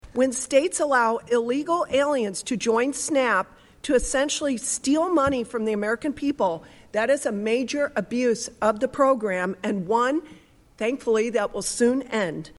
During a U.S. House hearing on Tuesday, Miller zeroed in on one of her sharpest criticisms: that some states allow undocumented immigrants to improperly access benefits.